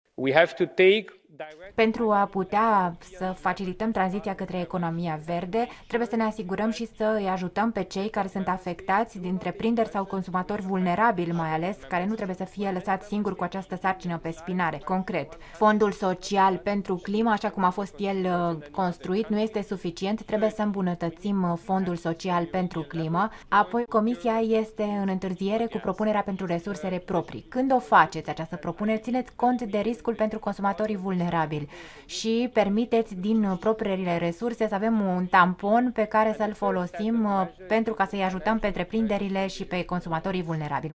06oct-13-Muresan-tradus-propuneri-criza-energie-.mp3